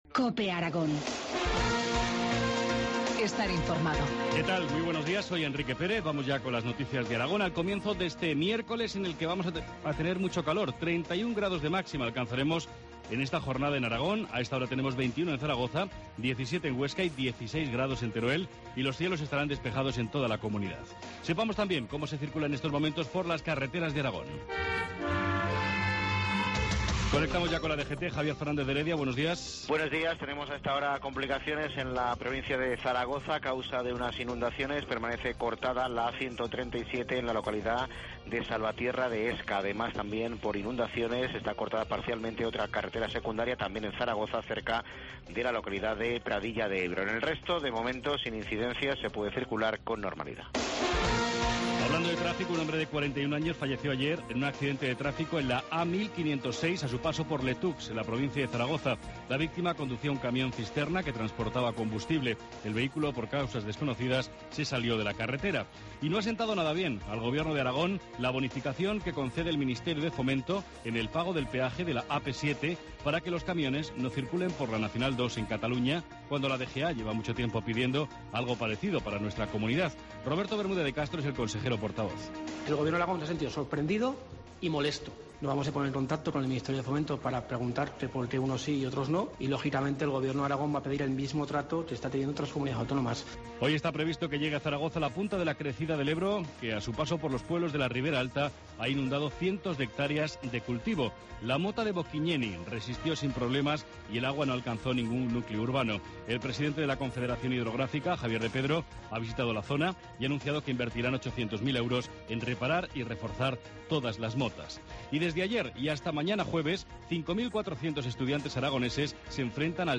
Informativo matinal, miércoles 12 de junio, 7.25 horas